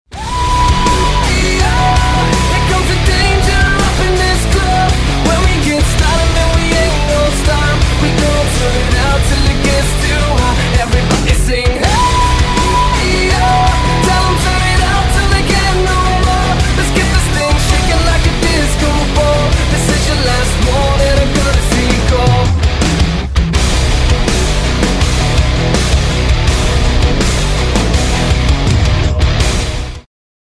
• Качество: 320, Stereo
громкие
nu metal
Hard rock